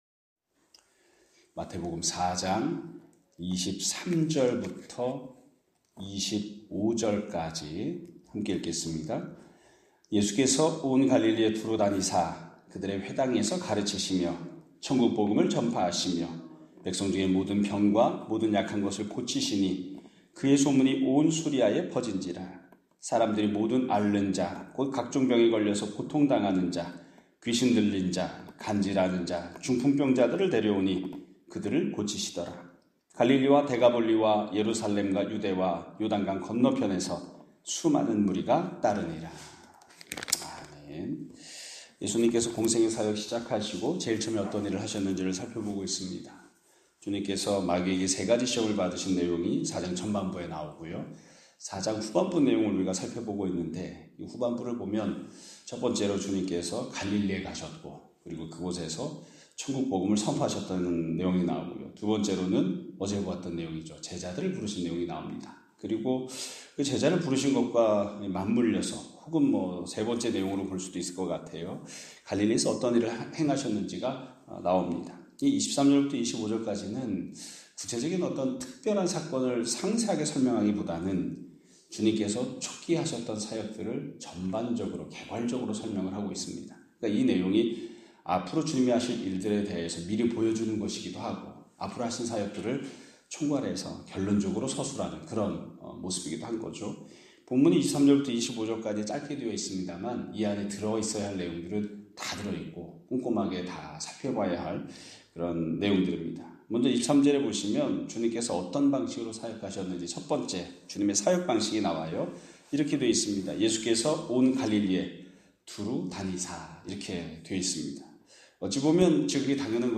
2025년 5월 2일(금 요일) <아침예배> 설교입니다.